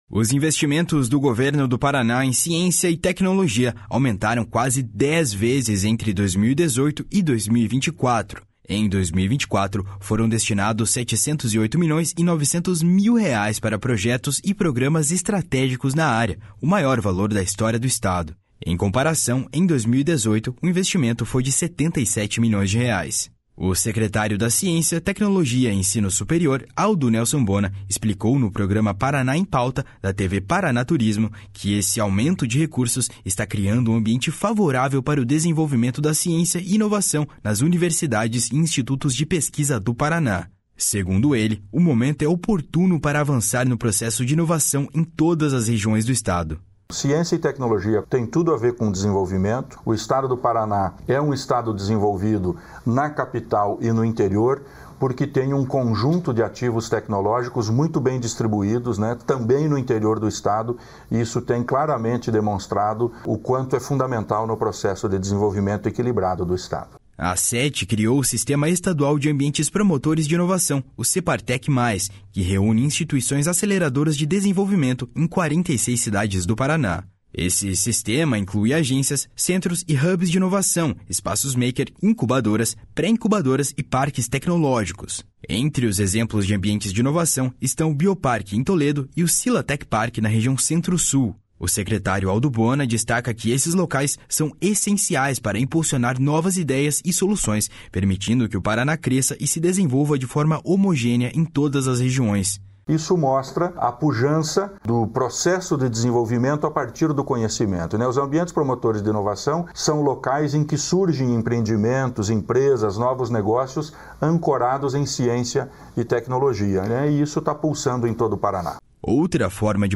Secretário detalha avanço da ciência e tecnologia em entrevista à TV Paraná Turismo